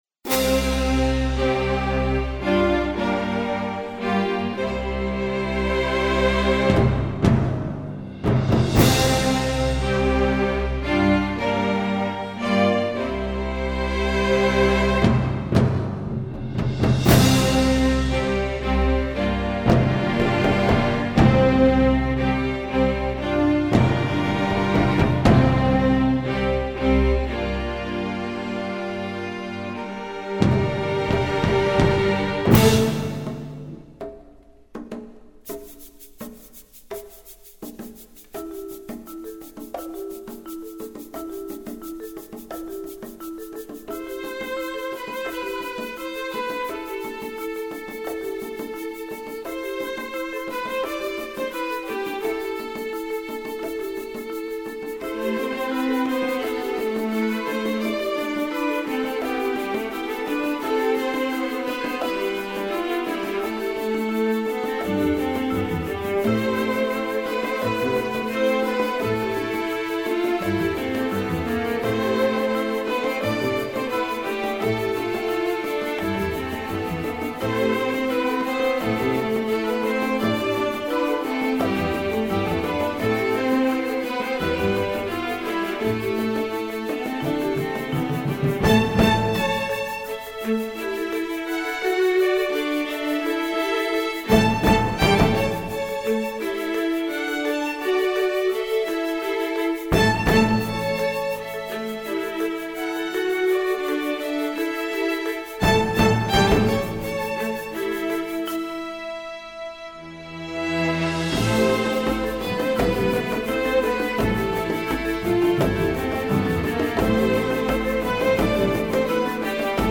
instructional, children